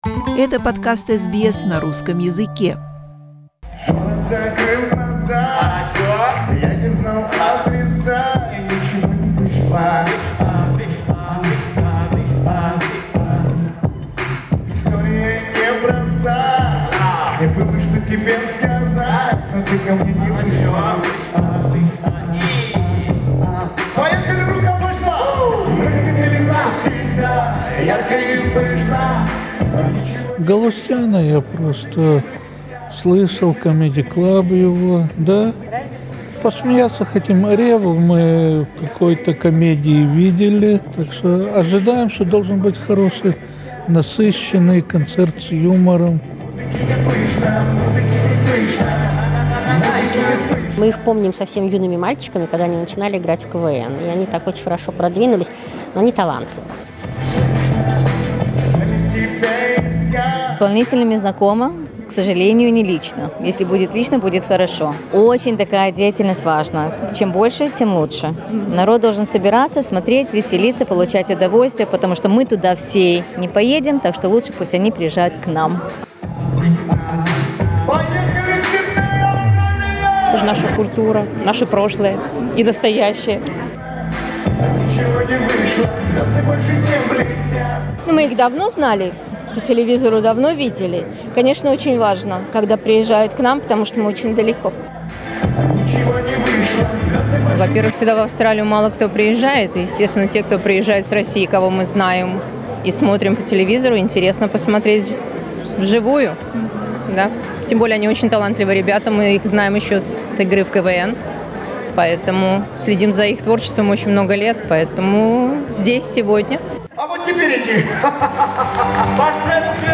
Las week, the Russian comedians Alexander Revva and Mikhail Galustyan performed in Melbourne and Sydney. If you missed it, we invite you "to visit" the Sydney's show now.